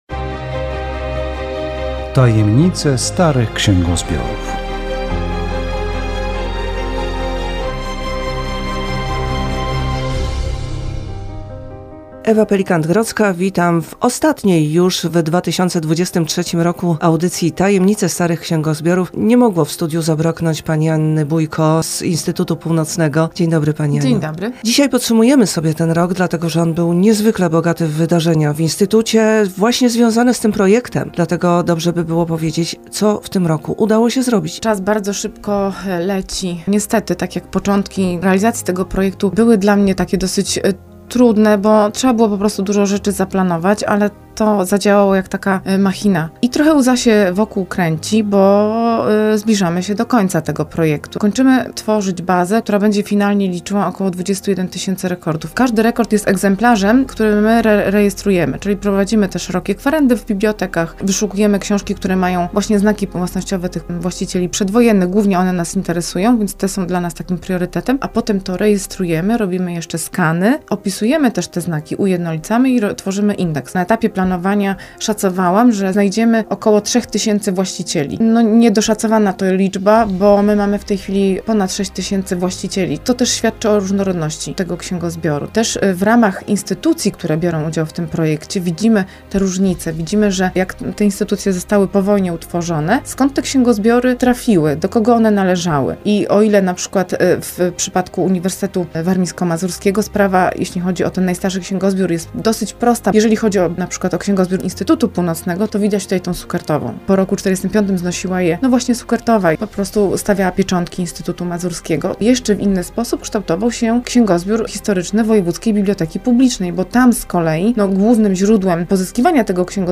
Audycja radiowa "Tajemnice starych księgozbiorów".